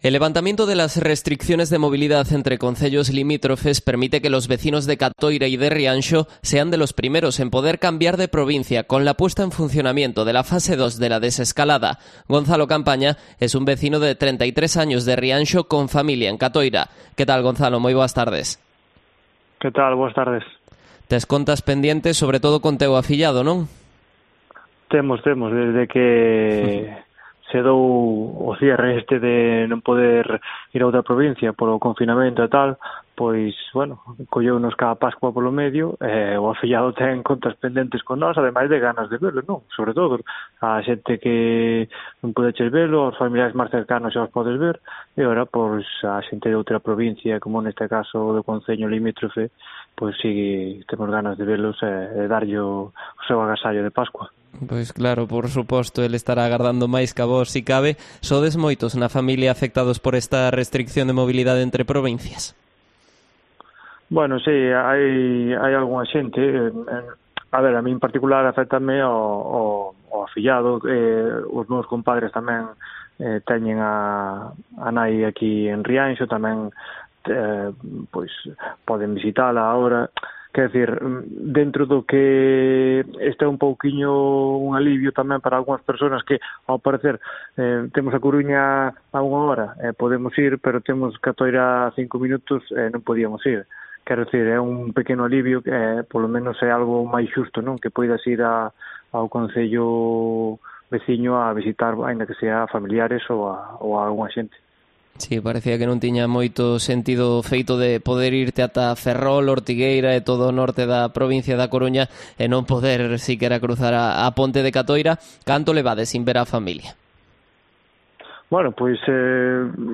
Entrevista a un vecino de Rianxo beneficiado por la Fase 2